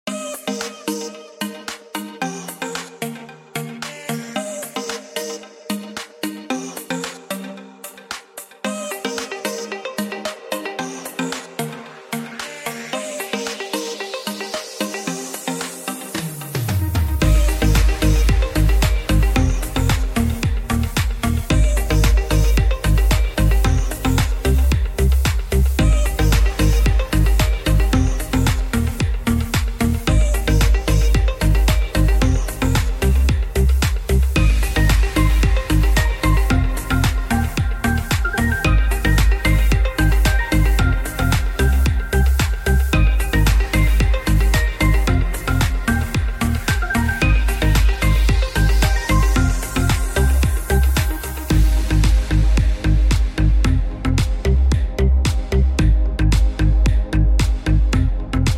Tropical House Instrumental Music